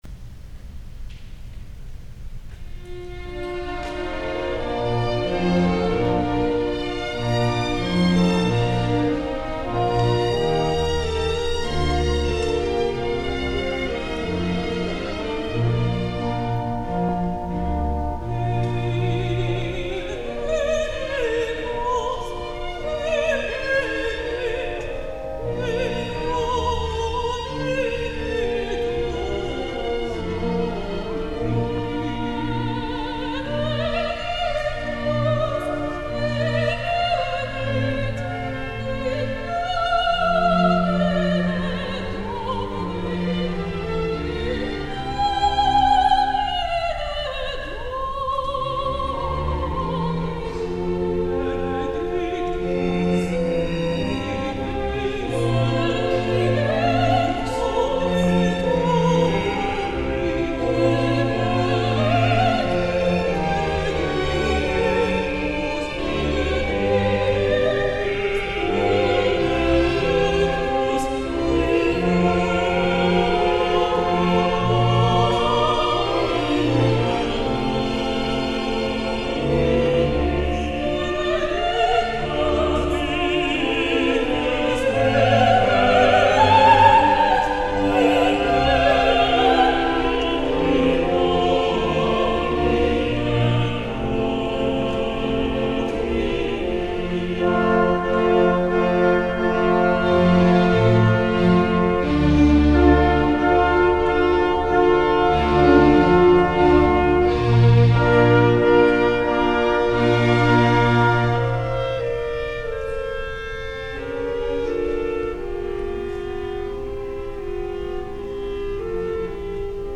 Kashiwa Concert Hall